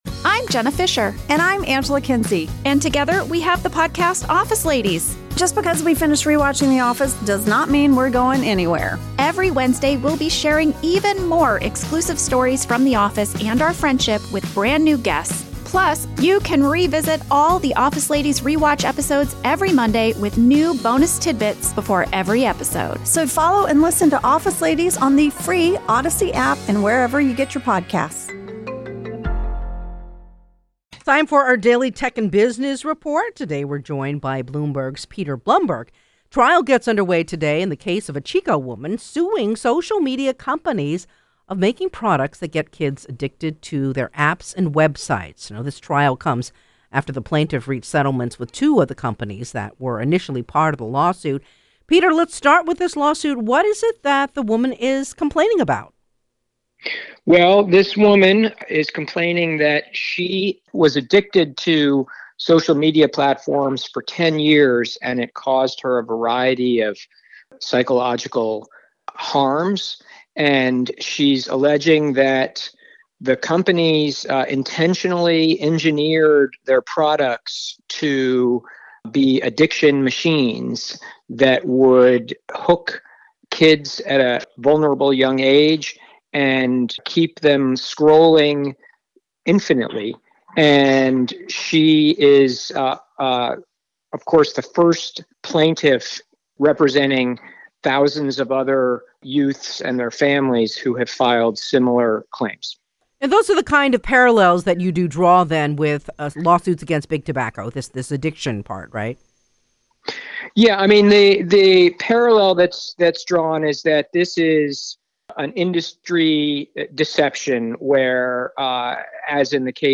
This is our daily Tech and Business report.